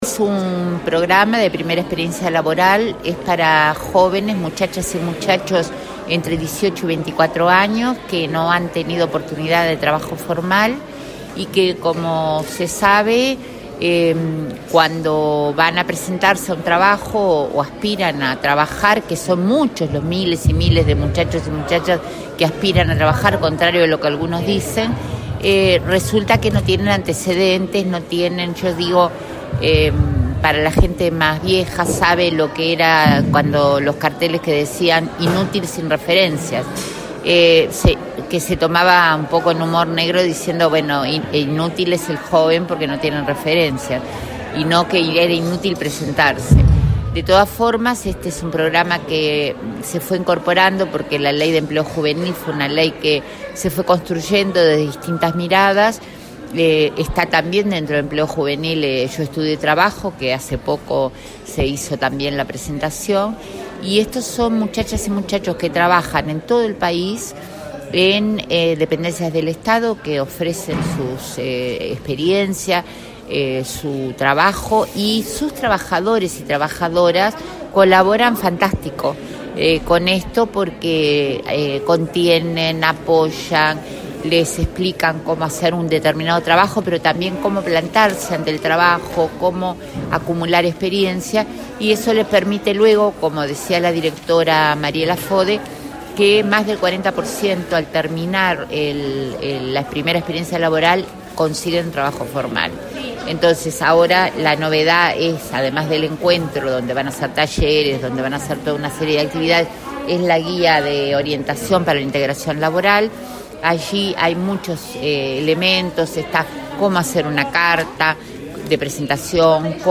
El primer encuentro de jóvenes que participaron del programa Primera Experiencia Laboral, realizado este viernes en Montevideo, congregó a 220 beneficiarios, de un total de 900. Más del 40 % de estos jóvenes, de entre 18 y 24 años, consigue empleo formal al terminar esta experiencia, aseguró a la prensa la ministra de Desarrollo Social, Marina Arismendi.